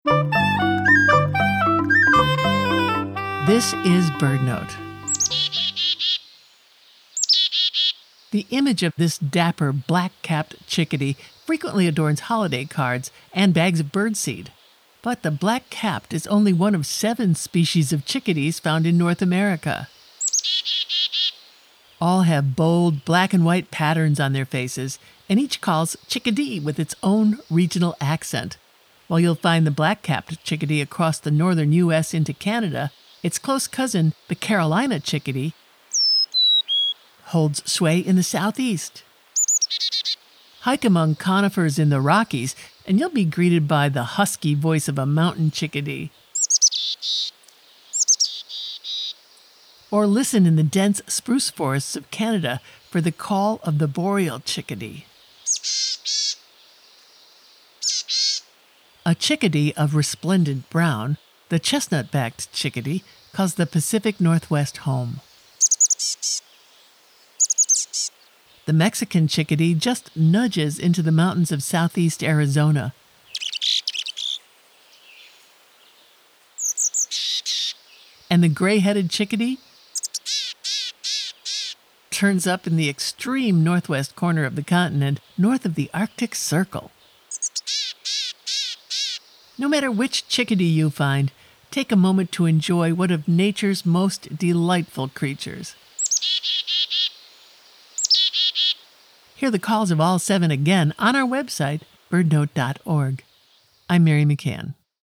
Chickadees are tiny songbirds named for their characteristic song and there are seven species found in North America.
Hear the husky voice of a Mountain Chickadee in the Rockies.